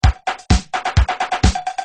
Free MP3 LinnDrum - LM1 & LM2 - Loops 4